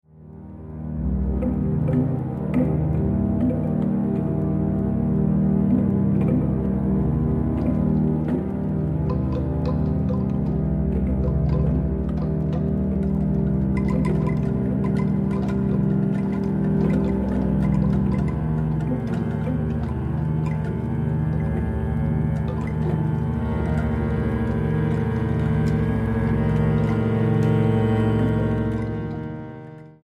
oboe y cinta